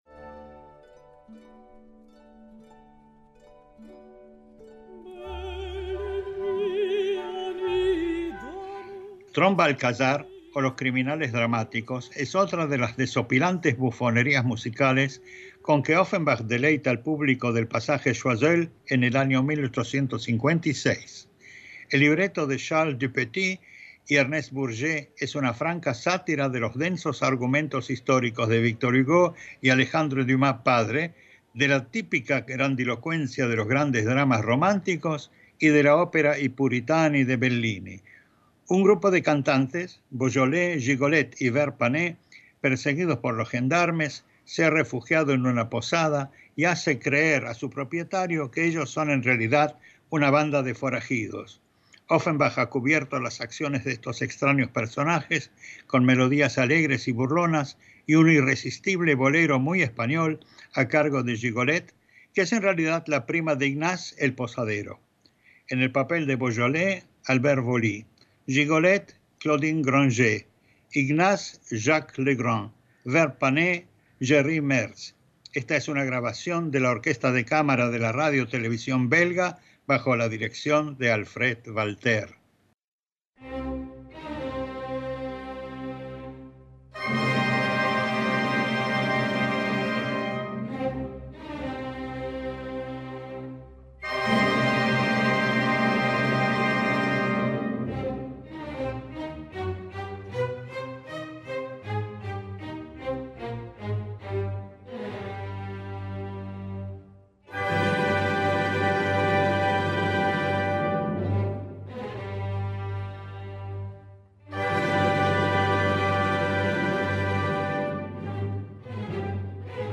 Offenbach ha cubierto las acciones de estos extraños personajes con melodías alegres y burlonas, y un irresistible bolero, muy español, a cargo